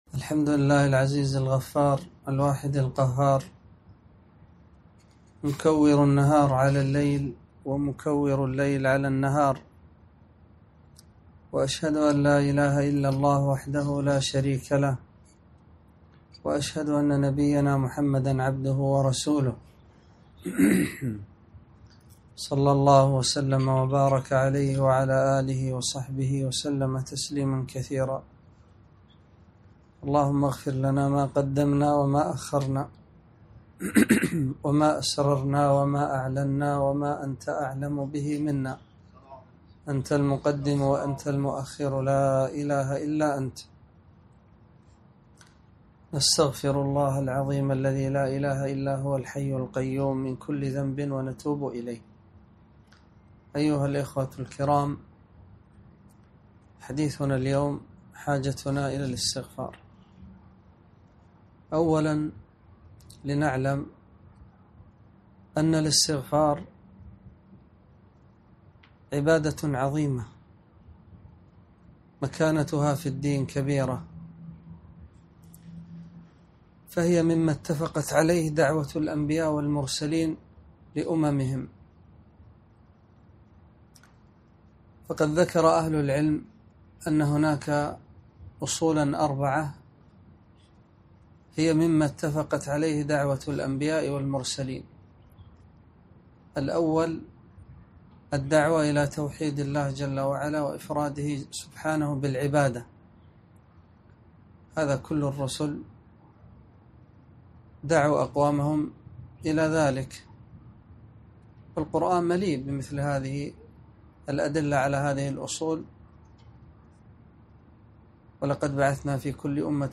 محاضرة - حاجتنا إلى الاستغفار